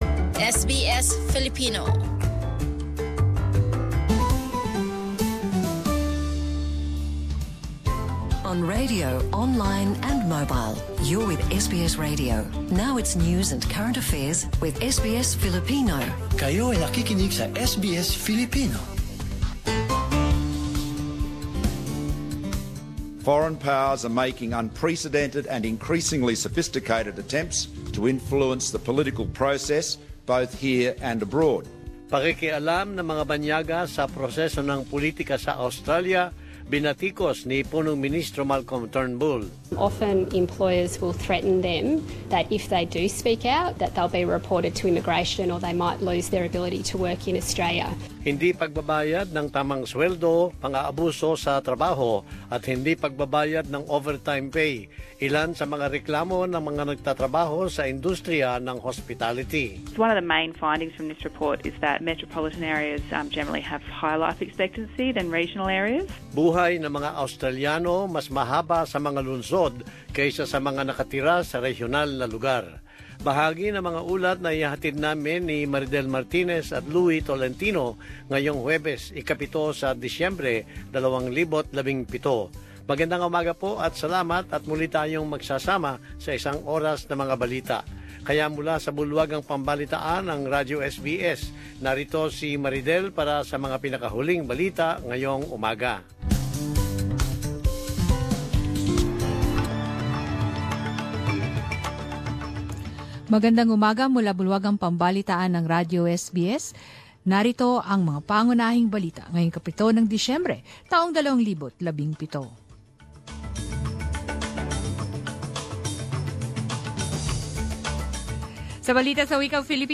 News bulletin at 10am in Filipino